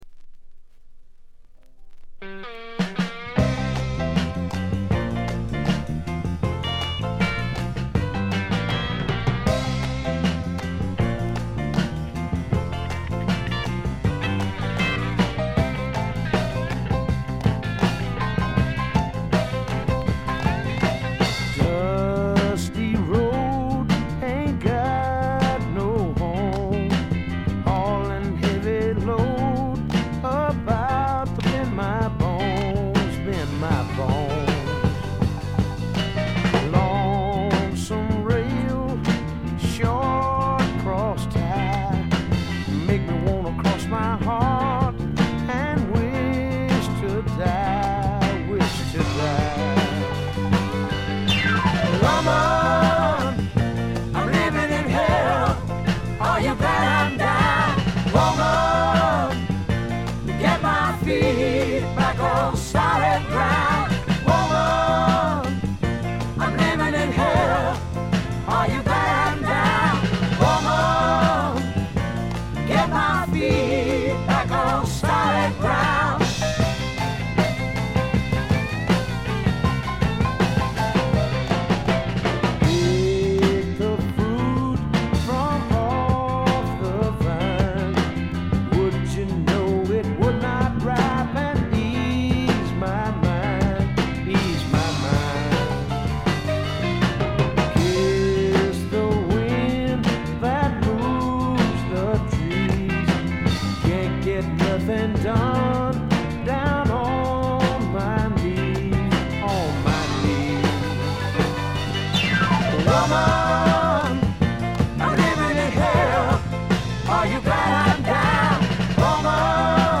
スワンプ基本盤。
試聴曲は現品からの取り込み音源です。